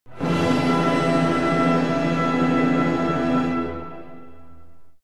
2. Orquestração de acordes - Tutti.
2.1.1. Com dinâmica forte.
2.1.1.1. Acordes perfeitos maiores.
Berliner Philharmoniker, Nikolaus Harnoncourt
Note ainda que em ambos os exemplos acima, tanto as cordas quanto as madeiras e os metais contém todos os membros do acorde.